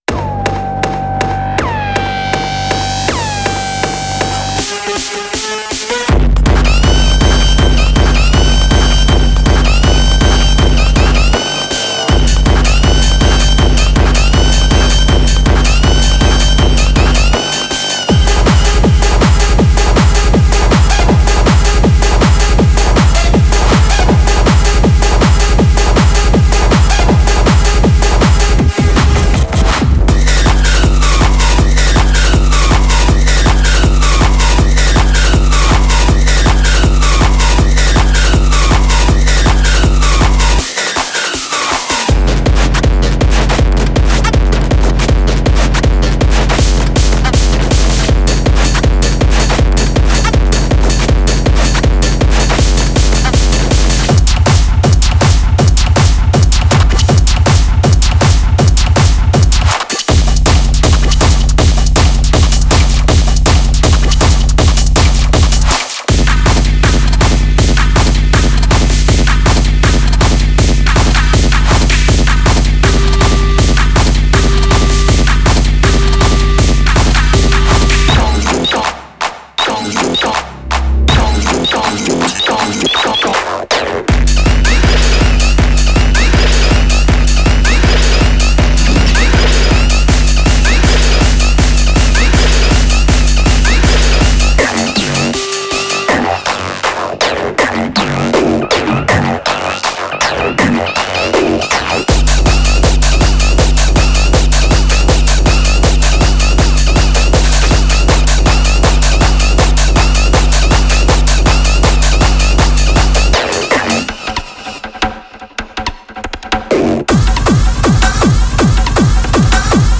現代のエレクトロニックミュージックのために用意された強烈なハードドラムショットのコレクションを引っ提げて帰ってきました。
個性的で重厚なキックが多数収録されており、すべて一から制作された完全オリジナル。
まさに音の炎です。
デモサウンドはコチラ↓
Genre:Hard Dance